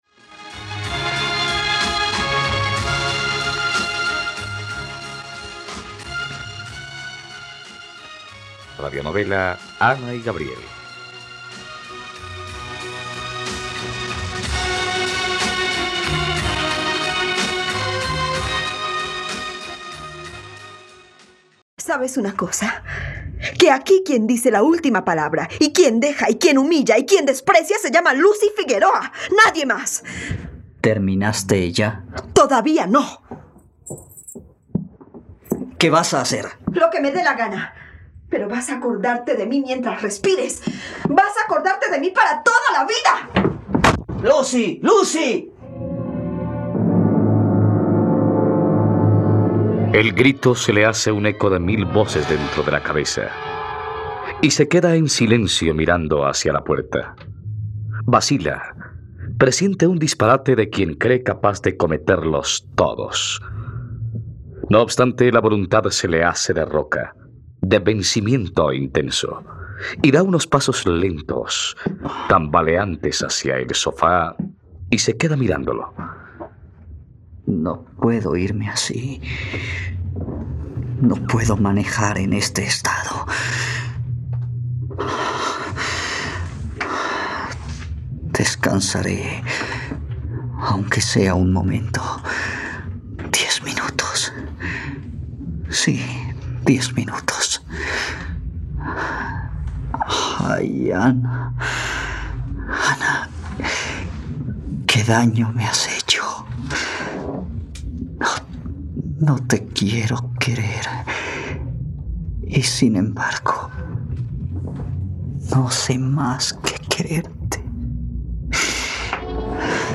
..Radionovela. Escucha ahora el capítulo 46 de la historia de amor de Ana y Gabriel en la plataforma de streaming de los colombianos: RTVCPlay.